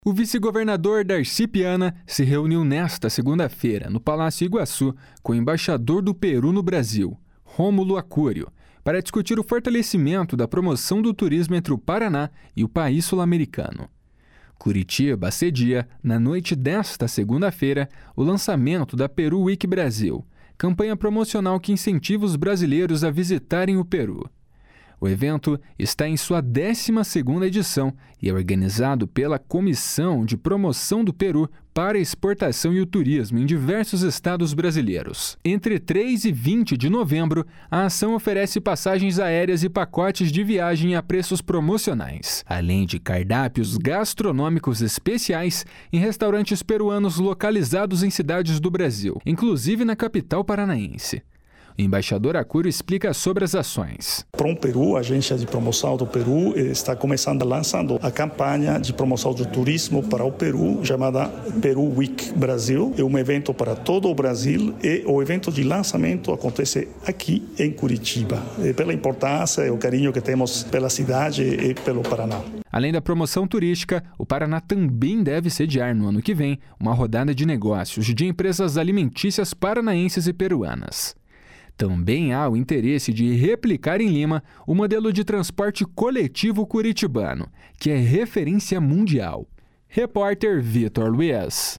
O embaixador Acurio explica sobre as ações.